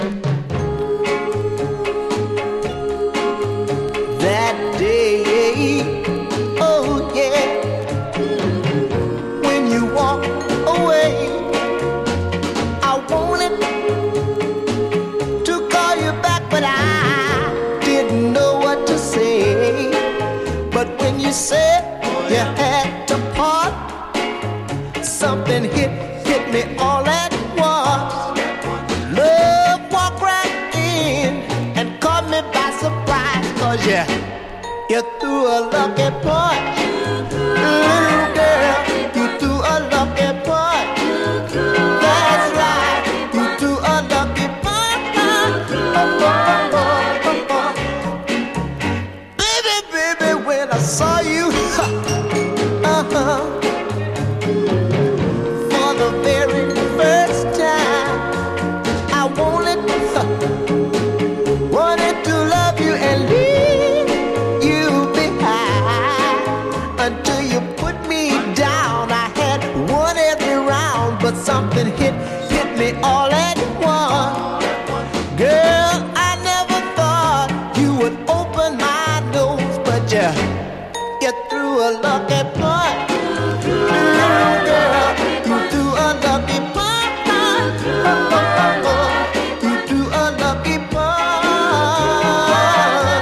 黄金のシカゴ・ノーザンソウル・クラシック！